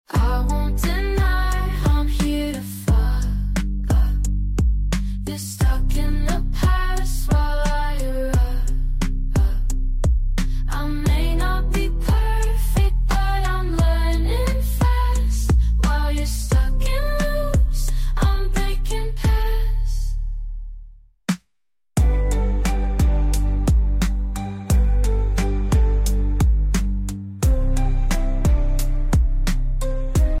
Pop Hook AI Generated Music and Lyrics